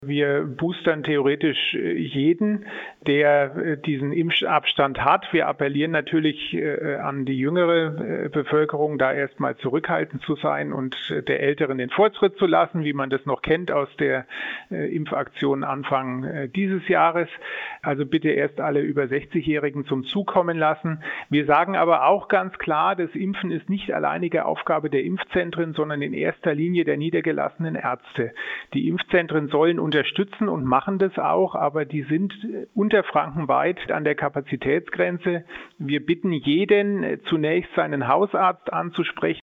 Impfkapazität, Boostern und 3G-Kontrollen: Schweinfurts Ordnungsreferent Jan von Lackum im Interview - PRIMATON